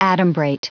Prononciation du mot adumbrate en anglais (fichier audio)
Prononciation du mot : adumbrate